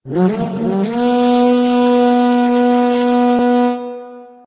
HornSmall.ogg